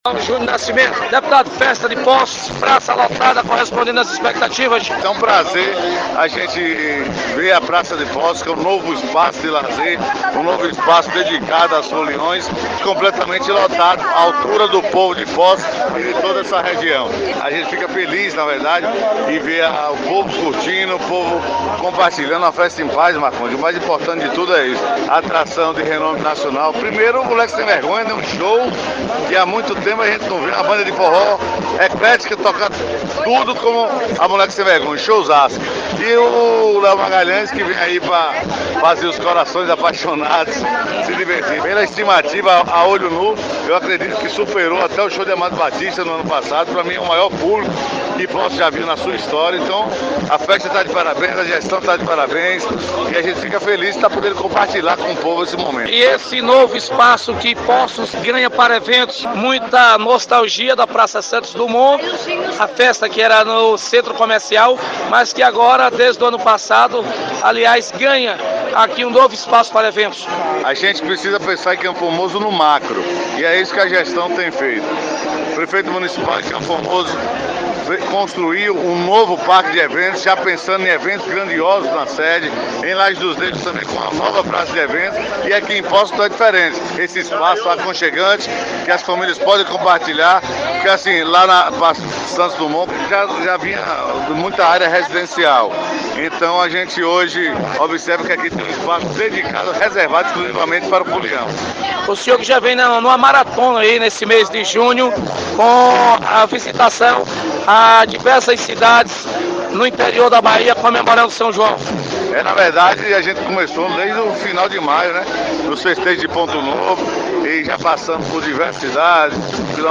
reportagem.mp3